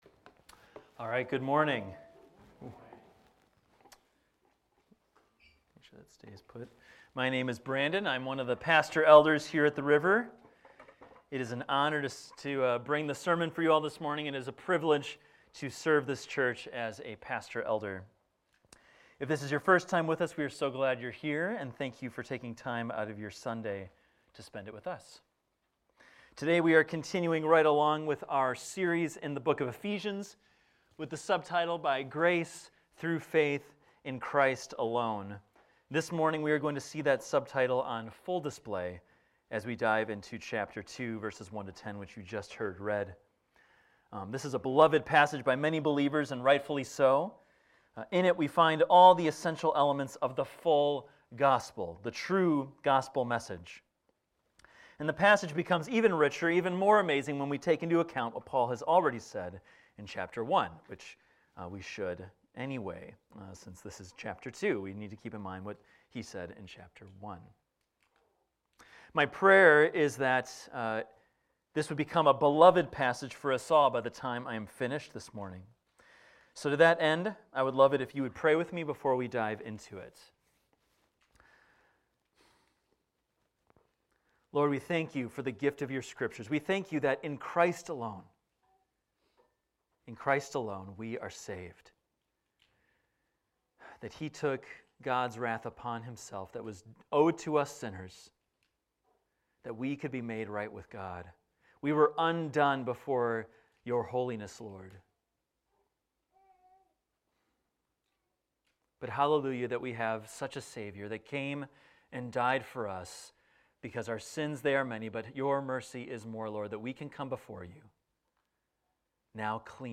This is a recording of a sermon titled, "From Death to Life."